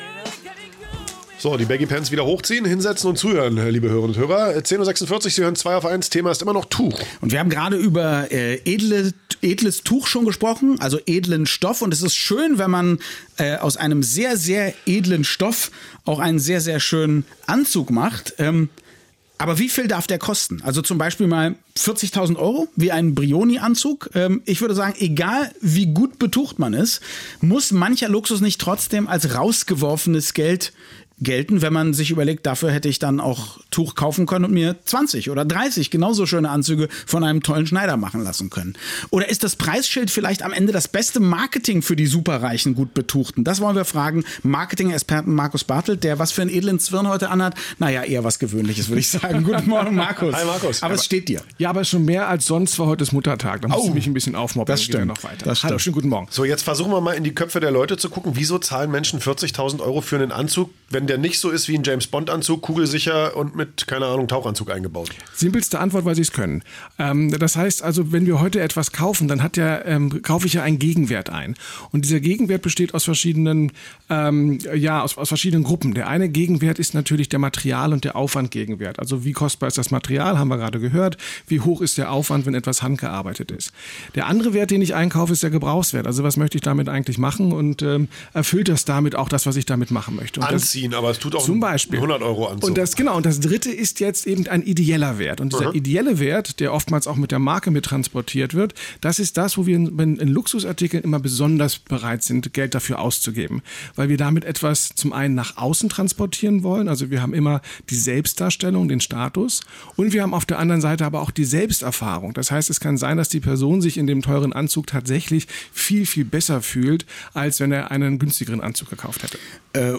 Die Herren von Zweiaufeins gelten ja als gut betucht – um diesen Status zu wahren, senden sie jeden Sonntag eine kluge Radioshow. Und auch heute habe ich meinen Luxuskörper nach Babelsberg geschwungen, um mit meiner Eloquenz zu protzen.